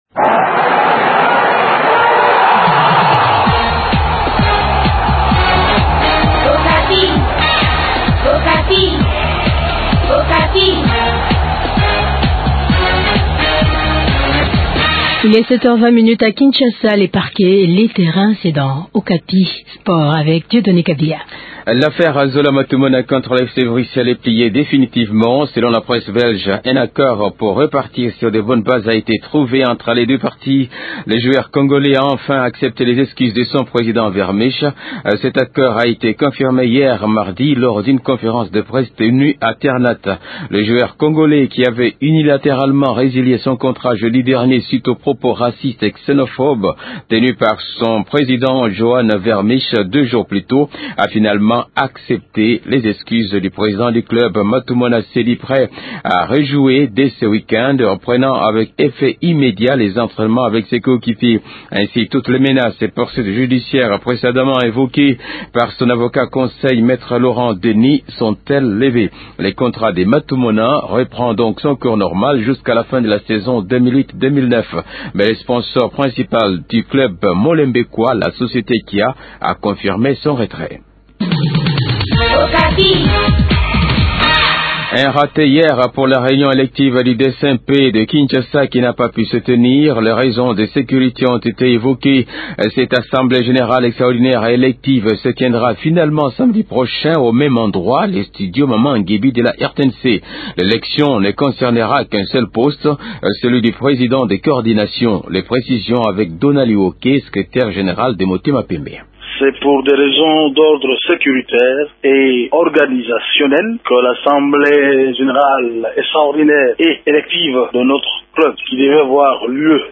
Vous pouvez réécouter cette interview dimanche prochain dans le magazine de sport.